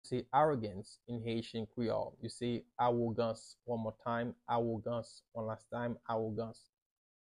“Arrogance” in Haitian Creole – “Awogans” pronunciation by a native Haitian Creole teacher
“Awogans” Pronunciation in Haitian Creole by a native Haitian can be heard in the audio here or in the video below:
How-to-say-Arrogance-in-Haitian-Creole-–-Awogans-pronunciation-by-a-native-Haitian-Creole-teacher.mp3